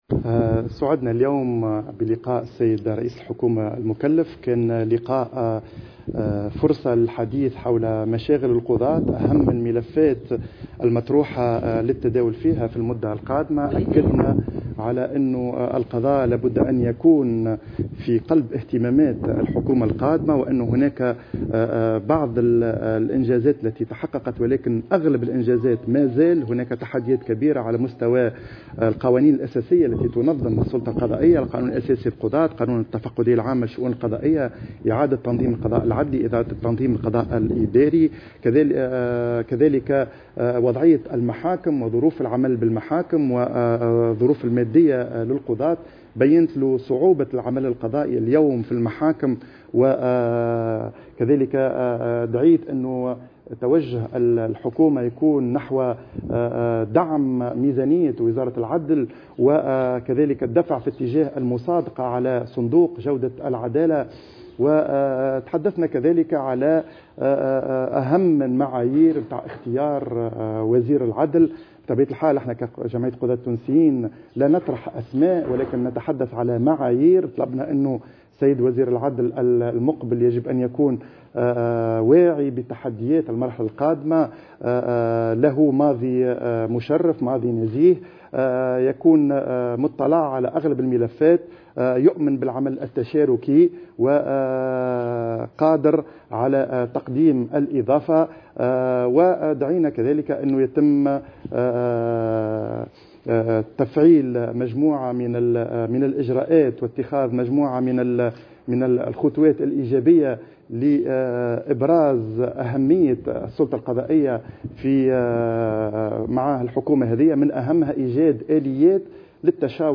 تصريح إعلامي